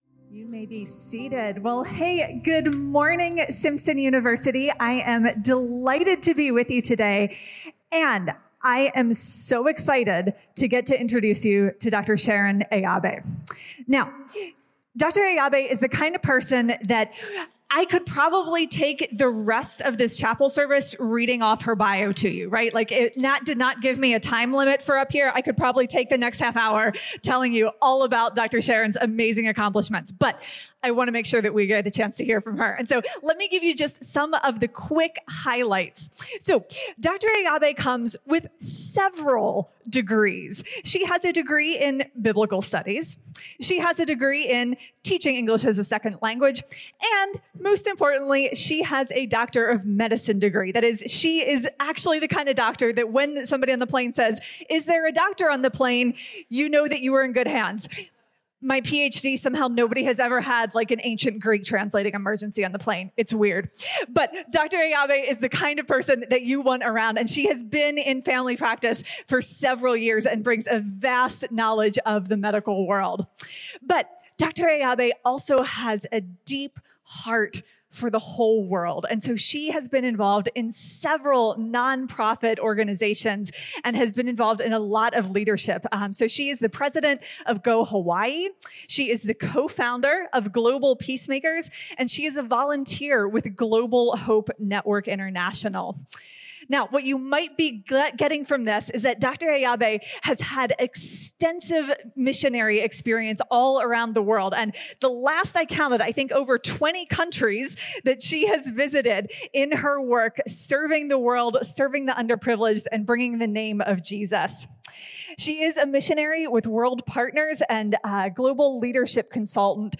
This talk was given in chapel on Wednesday, February 26th, 2025 God Bless you.